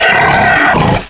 pain4.ogg